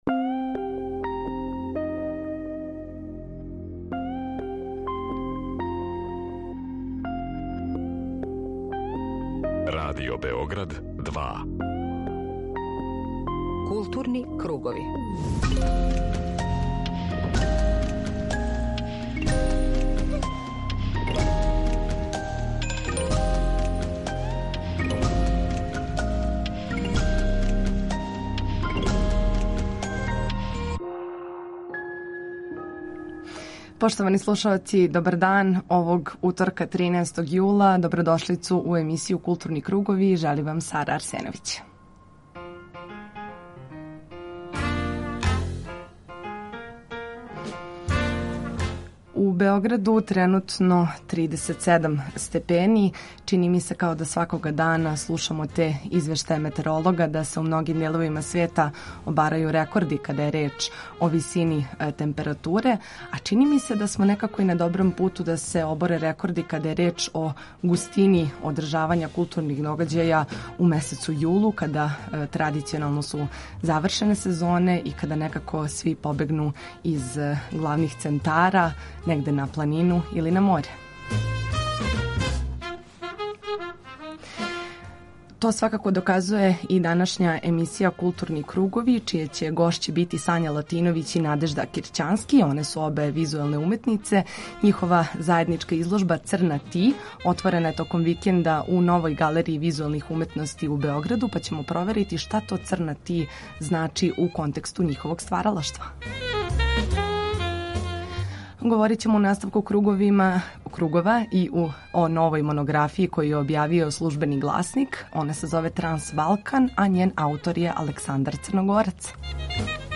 Централна културно-уметничка емисија Радио Београда 2.